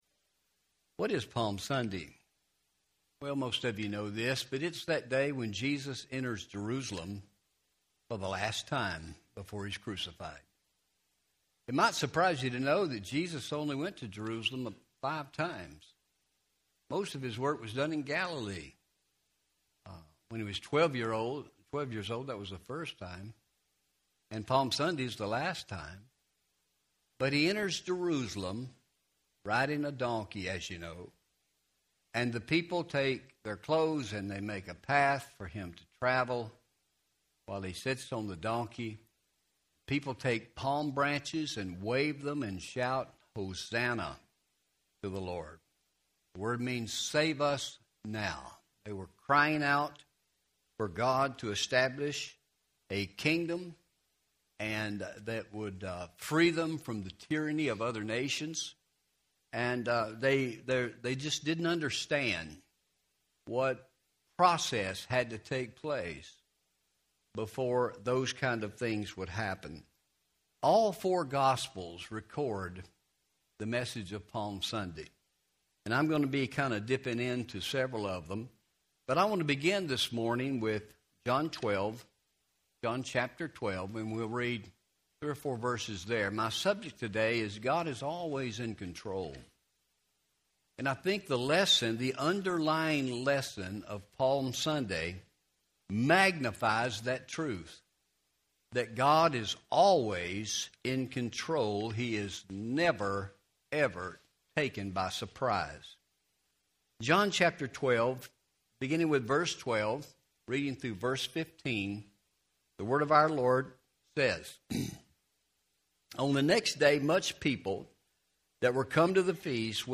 Palm Sunday Service 2026 Audio Sermon